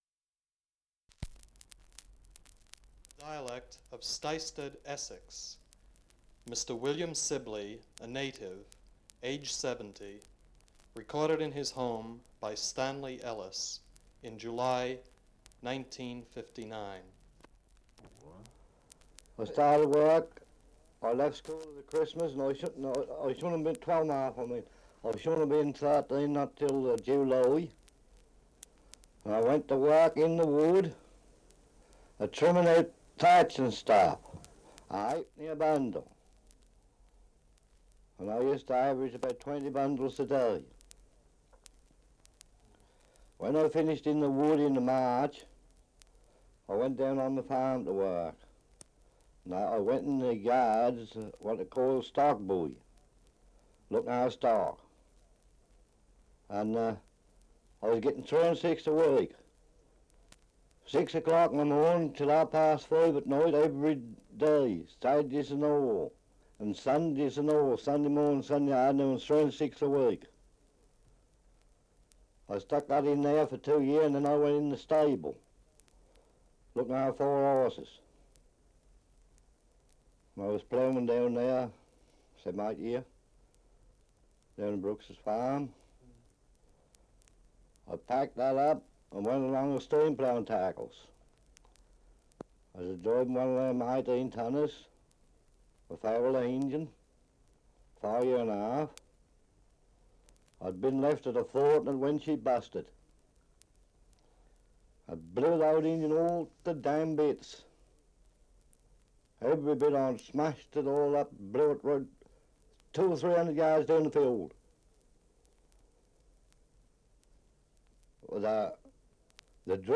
Survey of English Dialects recording in Stisted, Essex.
78 r.p.m., cellulose nitrate on aluminium